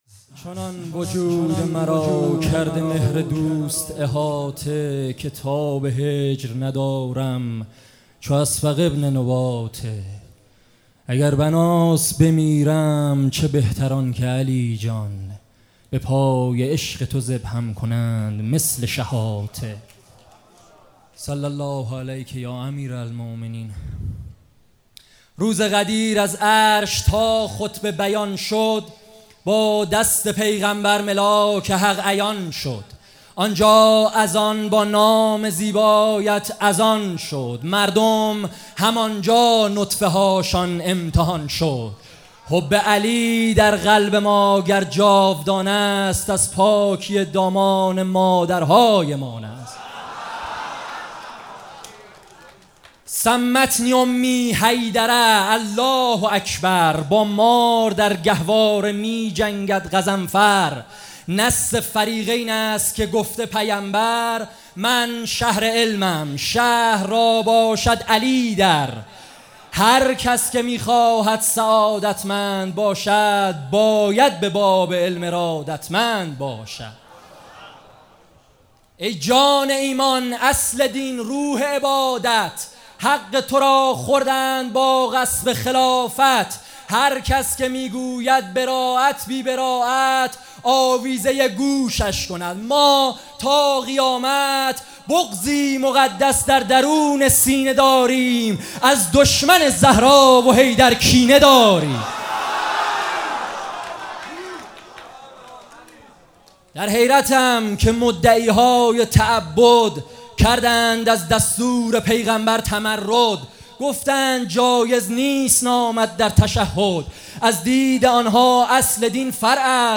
شب هشتم مراسم عزاداری شهادت حضرت زهرا سلام الله علیها 1402 - هیئت فدائیان حسین علیه السلام - حاج سید رضا نریمانی
🔊 بخش ششم زمینه | شنیدم بهتری الحمدلله جدا از بستری الحمدلله / حاج سید رضا نریمانی | دریافت | متن